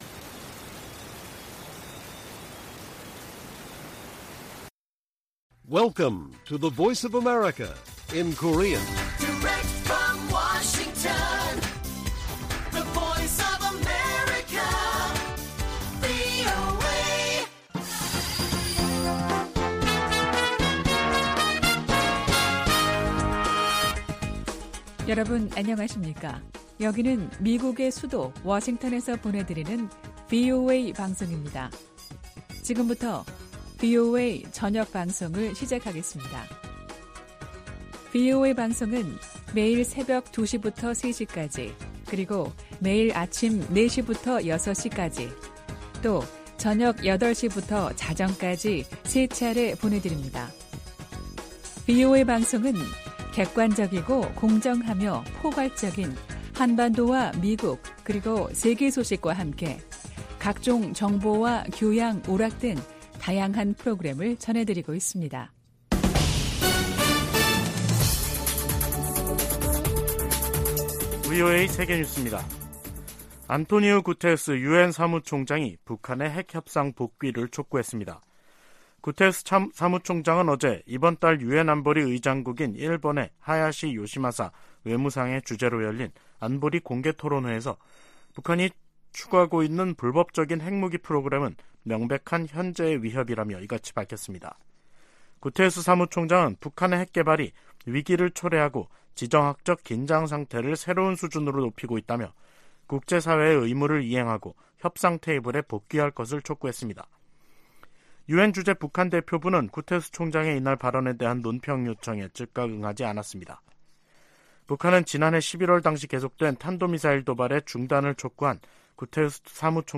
VOA 한국어 간판 뉴스 프로그램 '뉴스 투데이', 2023년 1월 13일 1부 방송입니다. 미 국무부는 미국과 한국, 일본이 북한의 핵과 탄도미사일 프로그램을 심각한 위협으로 받아들이고 있으며, 이를 막기 위해 3자 차원의 대응을 강화하고 있다고 밝혔습니다. 미국 백악관은 윤석열 한국 대통령의 자체 핵보유 언급과 관련해 한반도의 완전한 비핵화 입장에 변함이 없다고 강조했습니다.